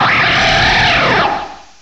cry_not_tapu_koko.aif